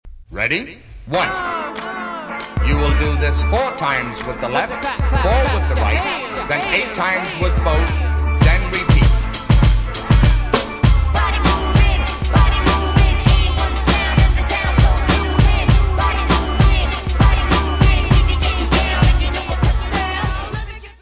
Área:  Pop / Rock